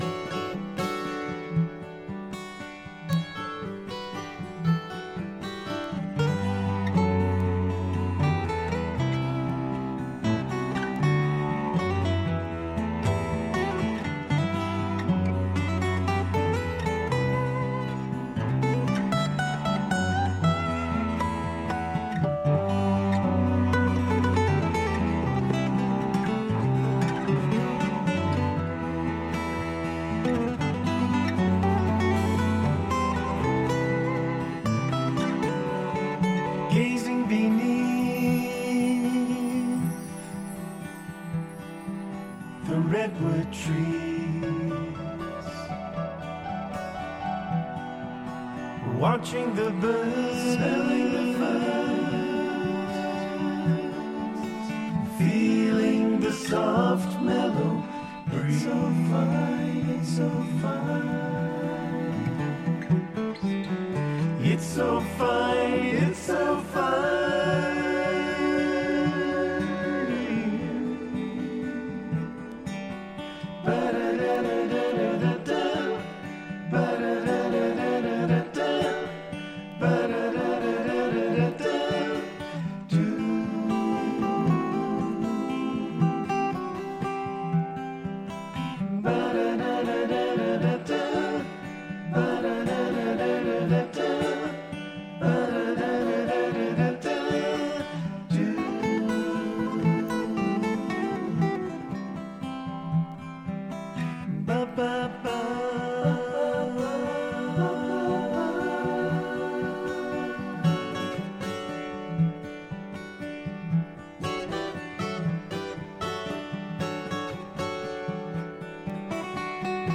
美しいメロディー、ハーモニーに思わず聴き入ってしまう傑作揃いです！